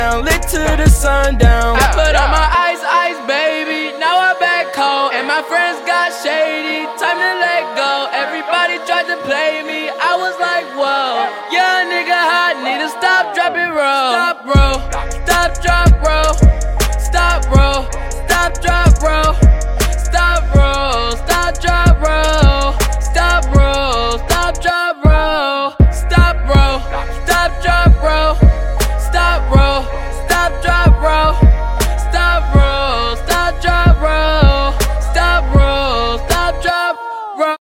Genres: Hip hop, trap, cloud rap